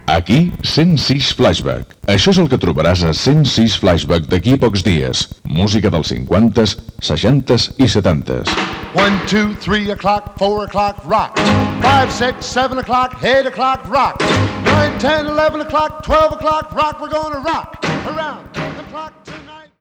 Gènere radiofònic Musical